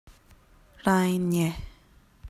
Rainë - rai-nye (roll the R) - Quenya - ‘peace’
A bit of a tricky pronunciation so here’s a voice recording.